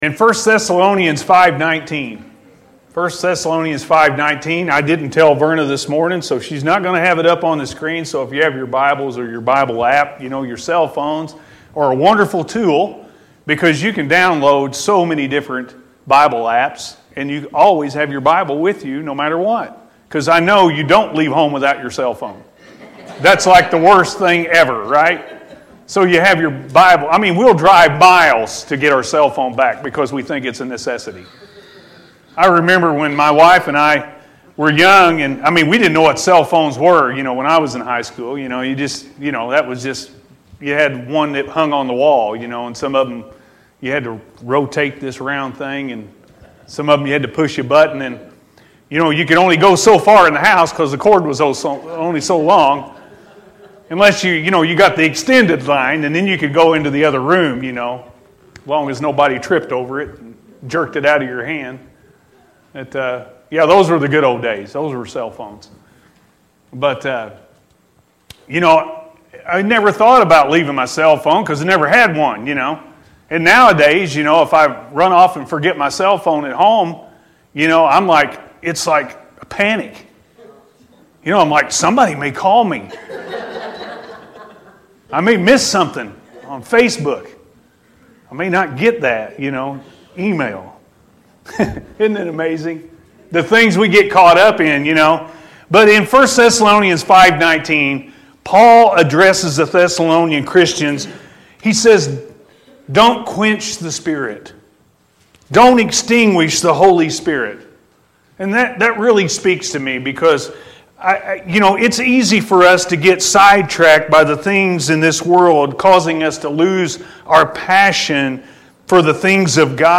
Keep The Flame Burning-A.M. Service